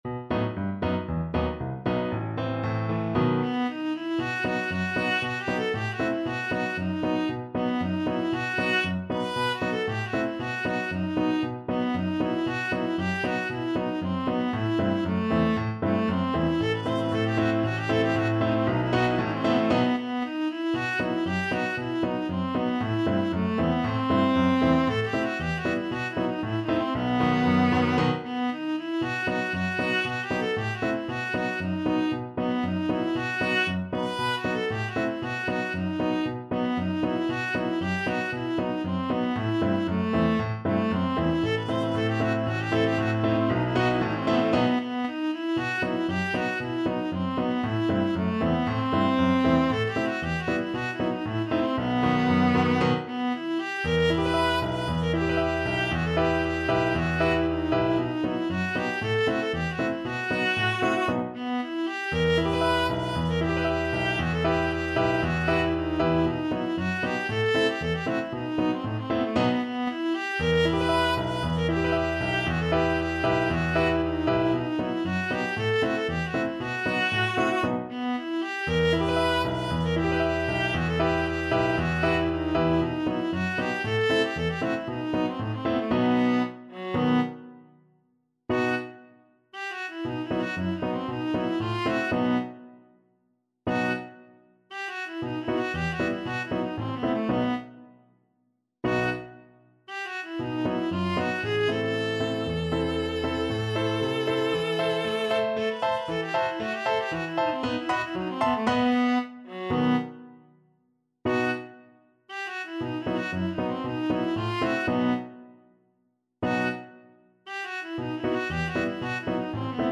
Traditional Trad. Der Heyser Bulgar (Klezmer) Viola version
Traditional Music of unknown author.
2/4 (View more 2/4 Music)
E minor (Sounding Pitch) (View more E minor Music for Viola )
Allegro =c.116 (View more music marked Allegro)